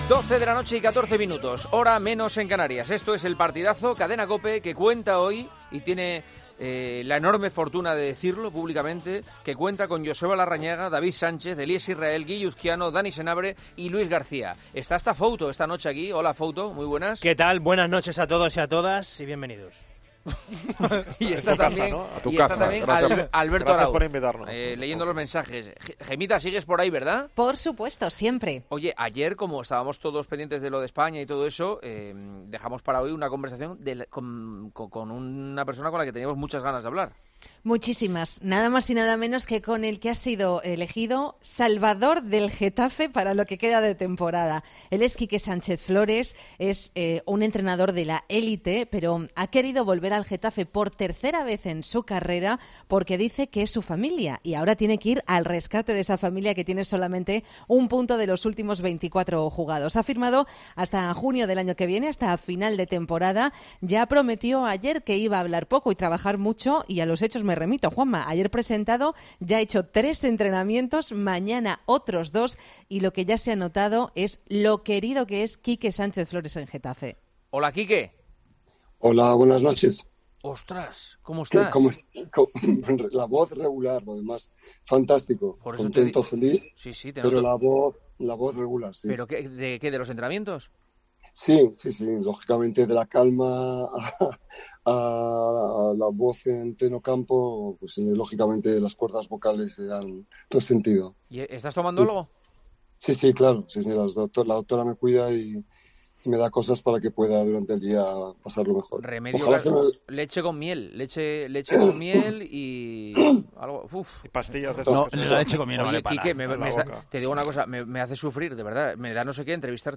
AUDIO - ENTREVISTA A QUIQUE SÁNCHEZ FLORES, EN EL PARTIDAZO DE COPE